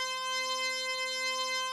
FARFISAISH 5.wav